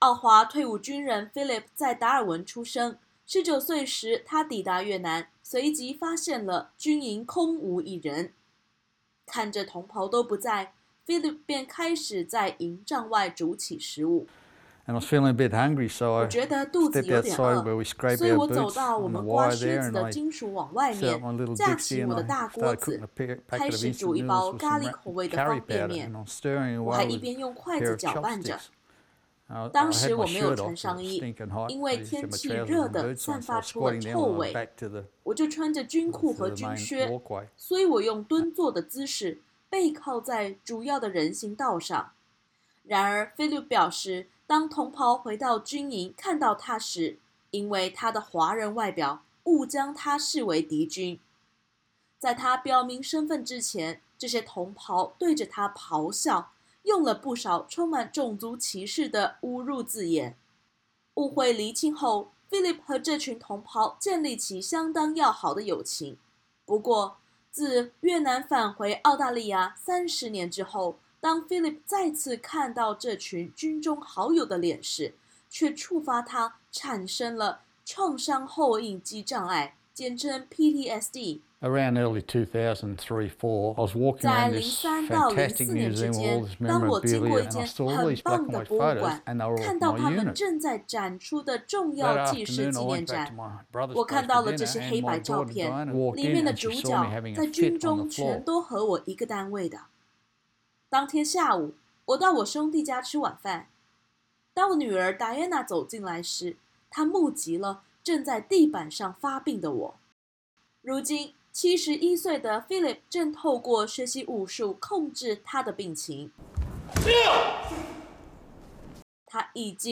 點擊首圖收聽寀訪音頻。